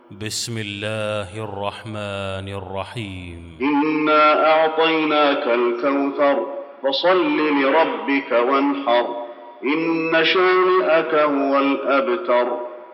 المكان: المسجد النبوي الكوثر